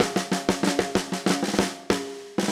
AM_MiliSnareA_95-02.wav